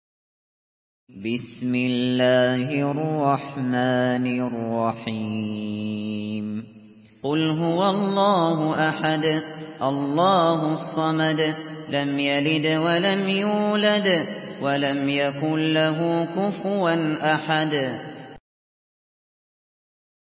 Surah Al-Ikhlas MP3 by Abu Bakr Al Shatri in Hafs An Asim narration.
Murattal Hafs An Asim